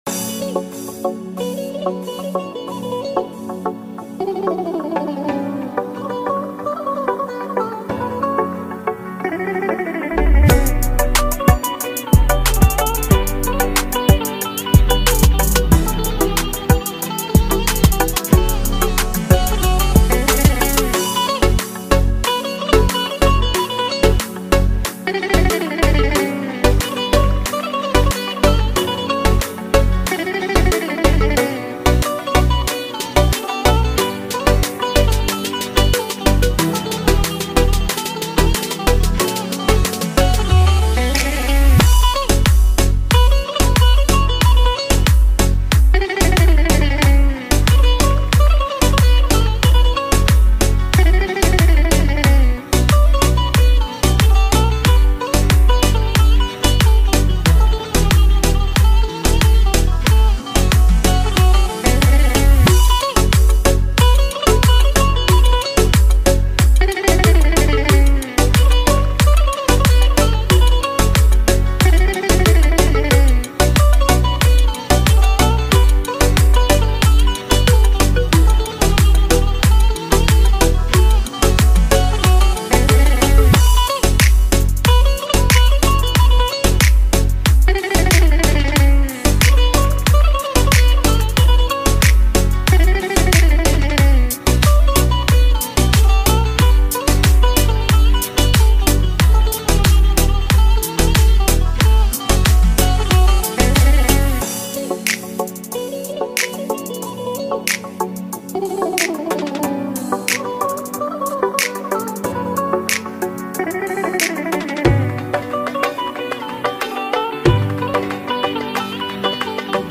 Новейшие и самые современные турецкие хиты 2026 года.